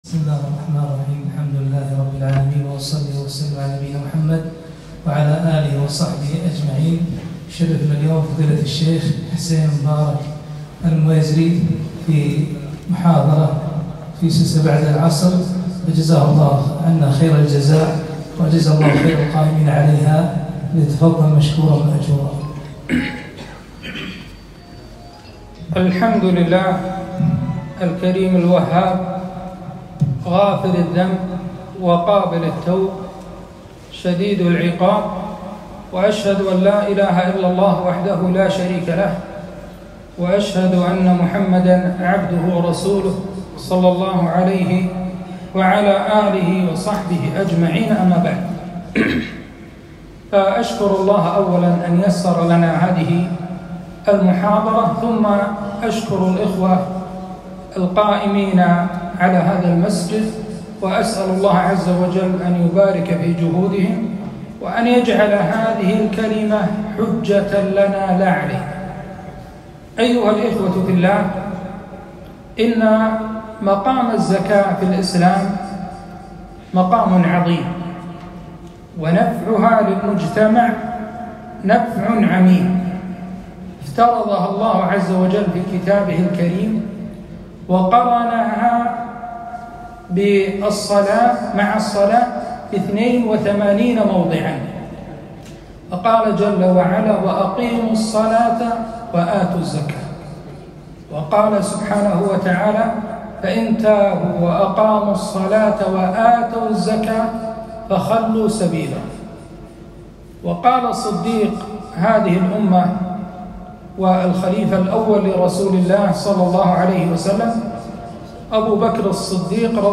محاضرة - خطر منع الزكاة